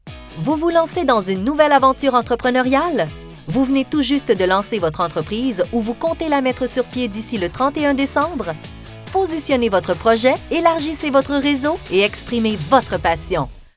PUBlicité – ton dynamique et soutenu